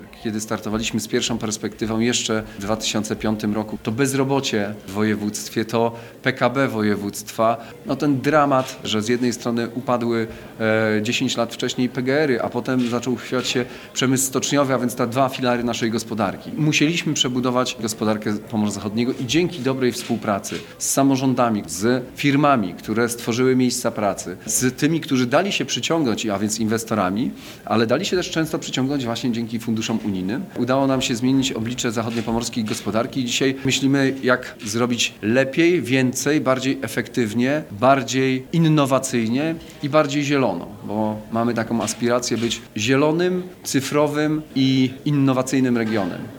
Dzisiaj Urząd Marszałkowski zorganizował konferencję dla samorządów i przedsiębiorców, by przygotować ich do wykorzystania eurofunduszy. Marszałek Olgierd Geblewicz wspominał pierwsze unijne pieniądze, które trafiły na Pomorze Zachodnie.